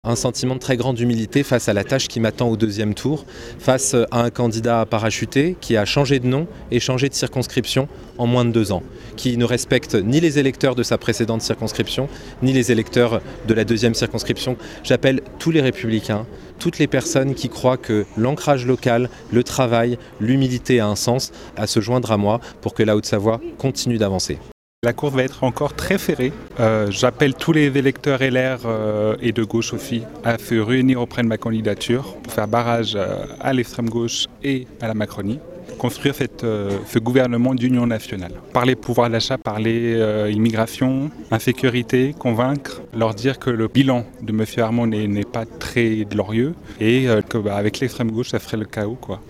Antoine Armand, Député sortant et candidat "Ensemble!" sur Annecy 2
Ecoutez les deux candidats de cette 2ème circonscription d'Annecy :